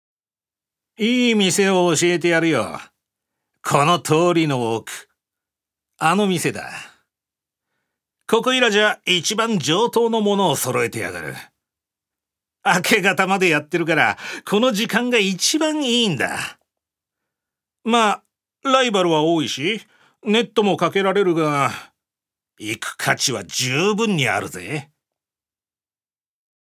所属：男性タレント
音声サンプル
セリフ１